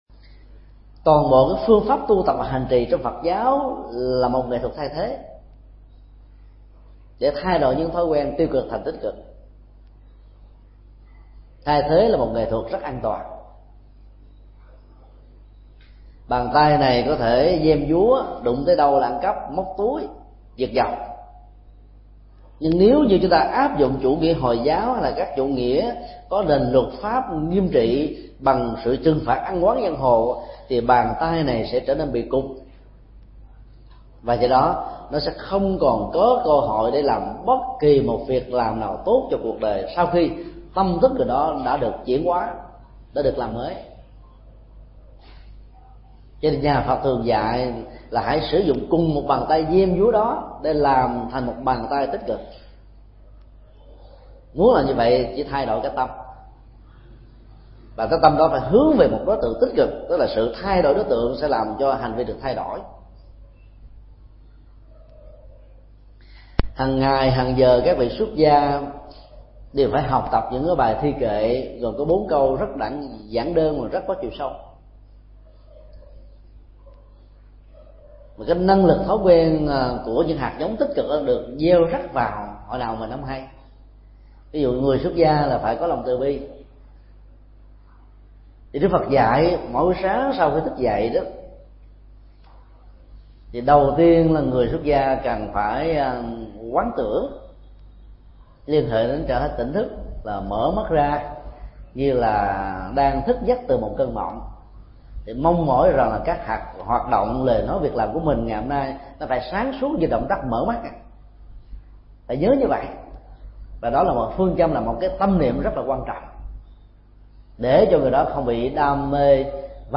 Thuyết pháp
Tải mp3 Pháp âm Chiến Thắng Thói Quen – Phần 2/2
tại Chùa Xá Lợi.